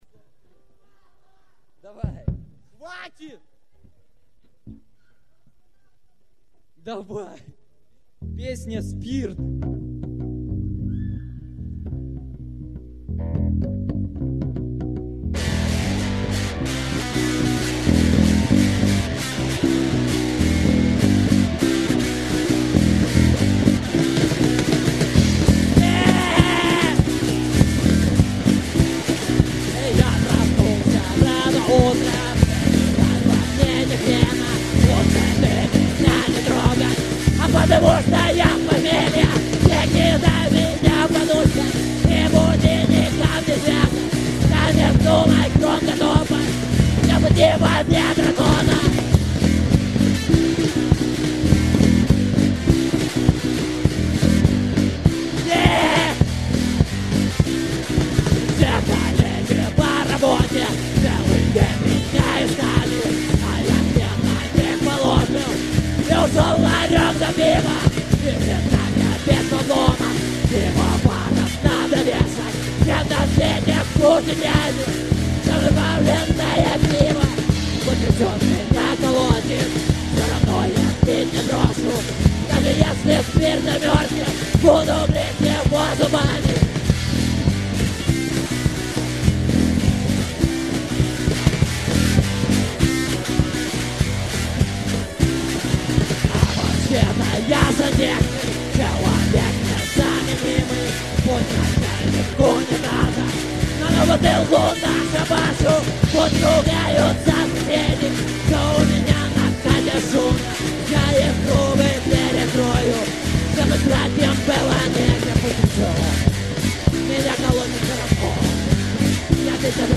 9: Записано с концерта в ДК ЛМЗ